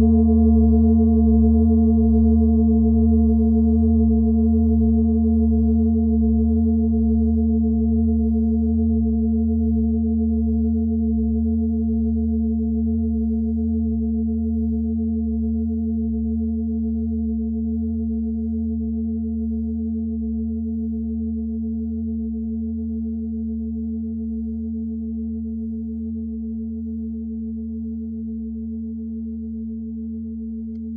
Klangschale Nepal Nr.51
(Ermittelt mit dem Filzklöppel)
Hören kann man diese Frequenz, indem man sie 32mal oktaviert, nämlich bei 154,66 Hz. In unserer Tonleiter befindet sich diese Frequenz nahe beim "D".
klangschale-nepal-51.wav